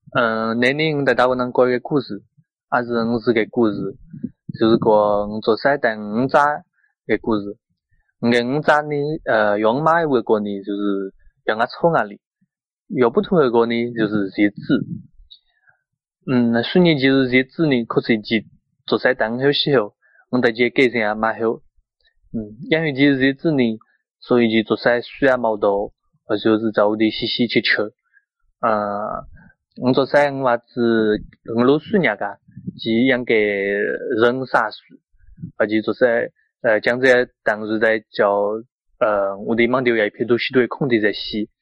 9 September 2014 at 9:18 am The answer is Wenzhounese (溫州話), specifically the dialect of Wenzhounese spoken in Hongqiao town (虹桥镇), Yueqing city (乐清市) in Wenzhou (温州) in Zhejiang Province (浙江省), China.